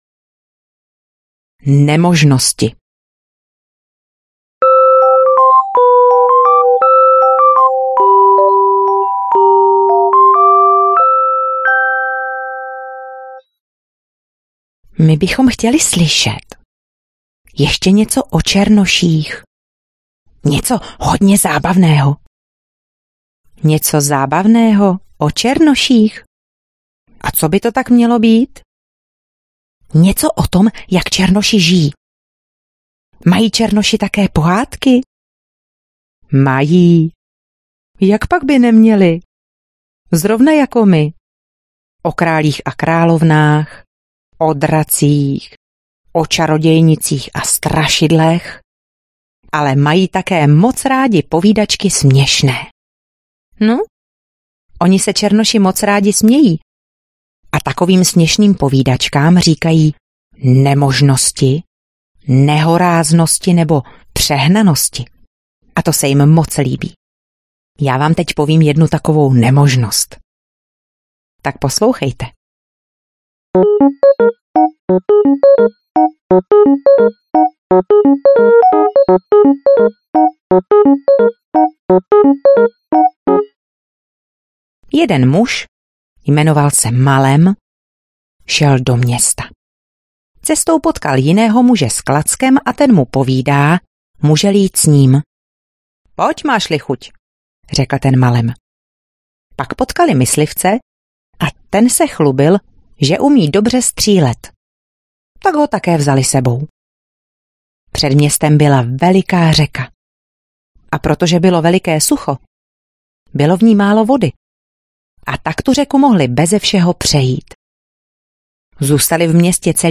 Povídejme si, děti audiokniha